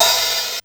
Rhythm Machine Sound "RZ-1"
openhihat.wav